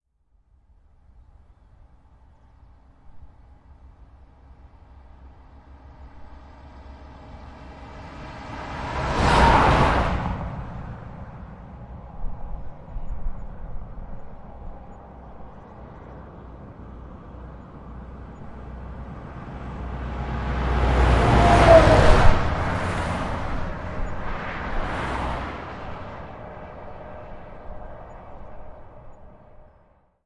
在这里，在乡村公路上，两辆卡车和两辆车通过自然多普勒效应。由我自己用Zoom H4录制。
Tag: 道路 环境 汽车 交通 汽车 卡车 领域 - 记录 噪音 大气